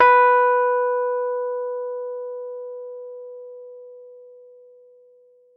Rhodes_MK1
b3.mp3